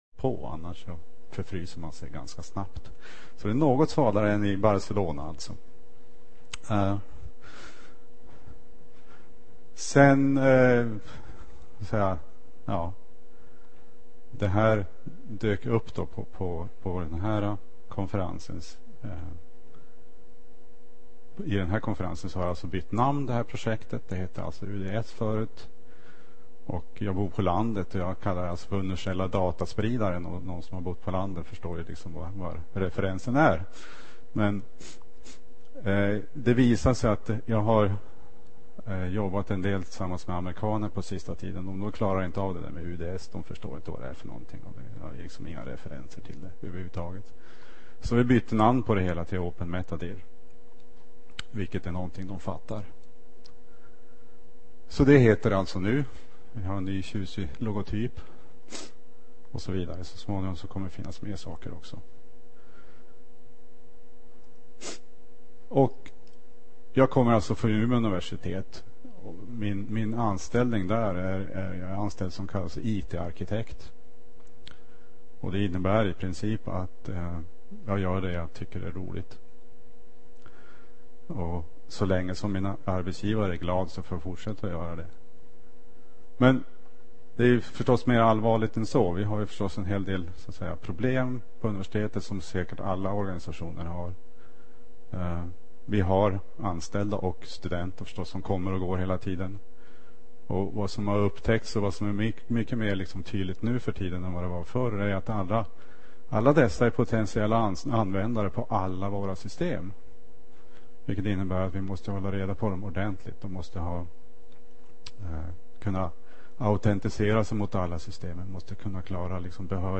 Trots den snabba spridningen av n�tverk under de g�ngna �ren finns det fortfarande gott om nya till�mpningsomr�den kvar att utveckla. Under seminariet presenteras tv� goda exempel p� intressanta nya n�tverkstill�mpningar.